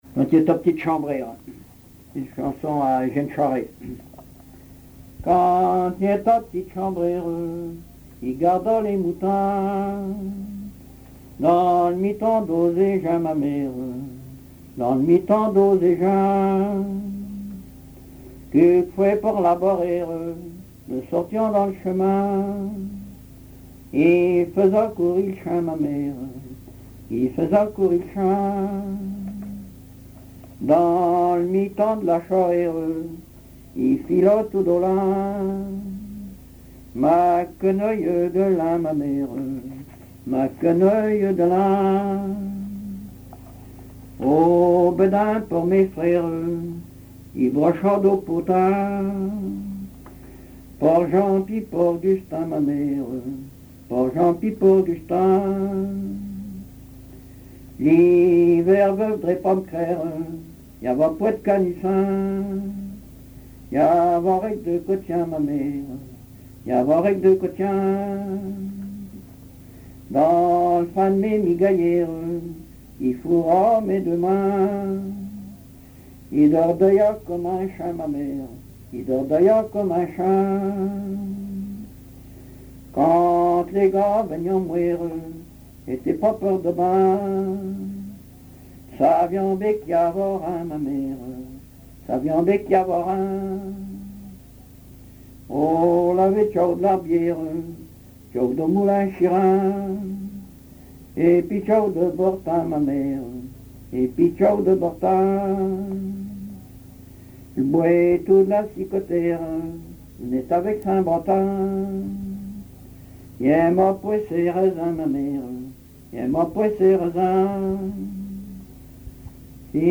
chansons populaires
Pièce musicale inédite